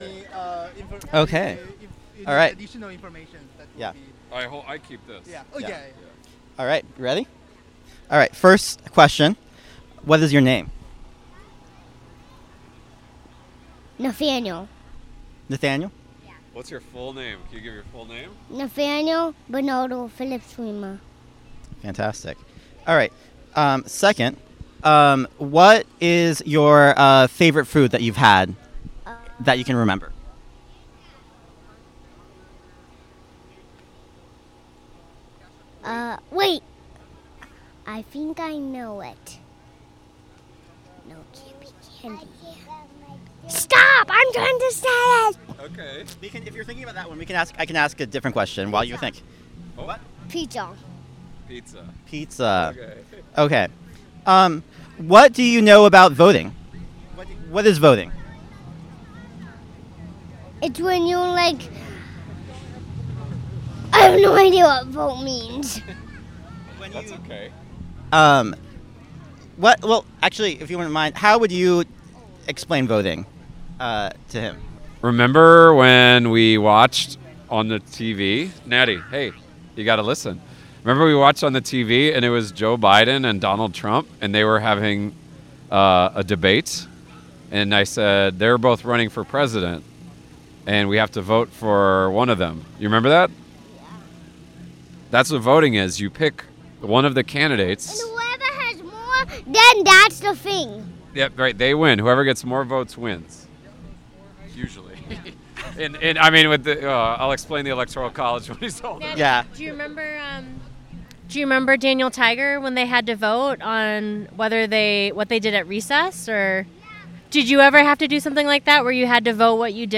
Location Jackson Park Farmer's Market